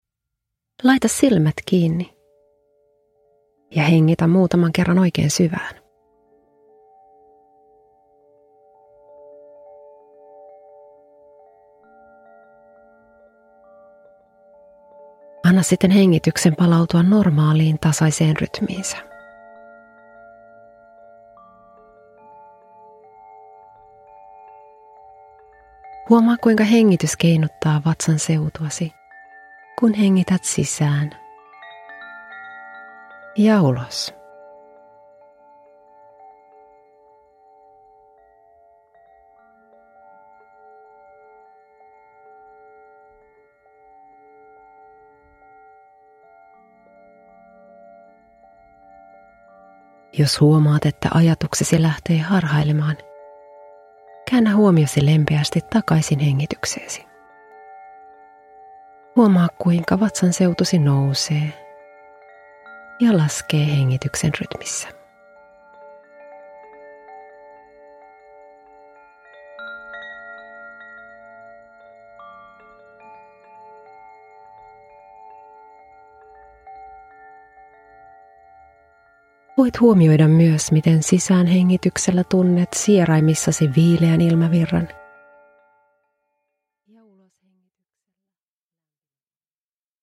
Stressinhallintameditaatio 15 min – Ljudbok – Laddas ner